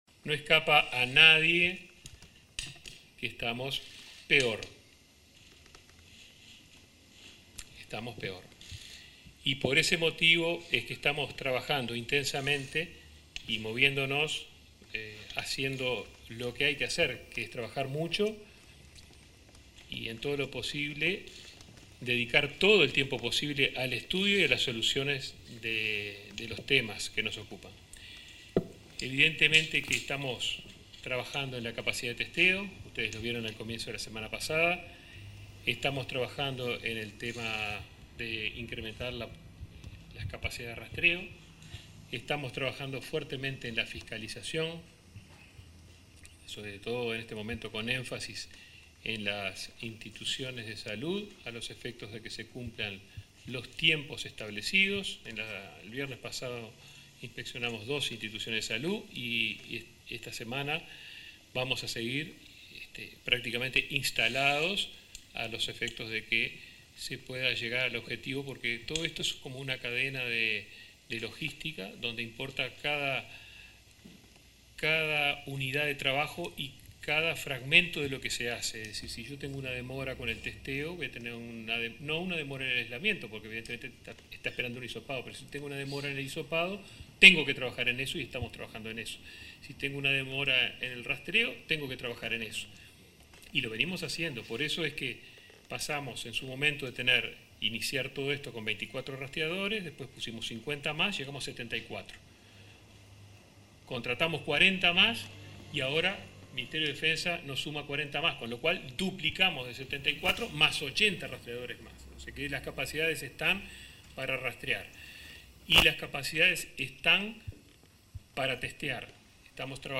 Declaraciones del ministro de Salud Pública, Daniel Salinas, en firma de acuerdo con Ministerio de Defensa